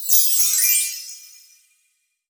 chimes_magical_bells_03.wav